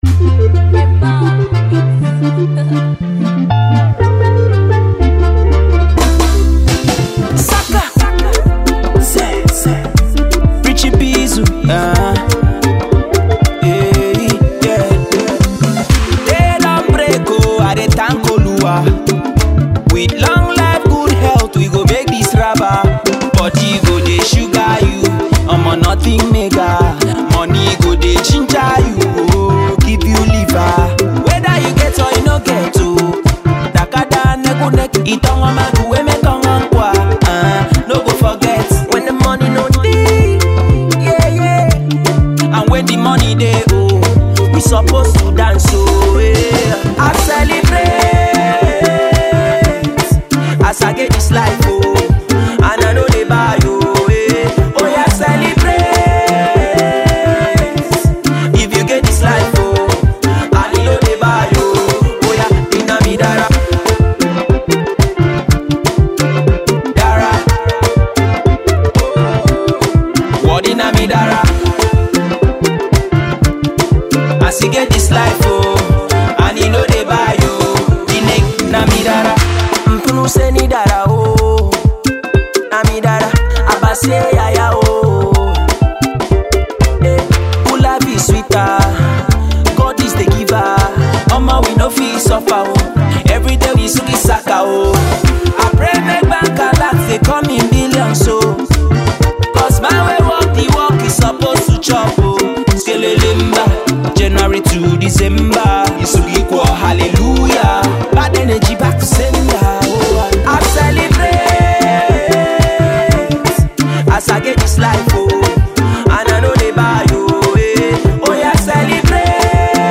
Afro-highlife tune
A happy song for celebration of life and goodwill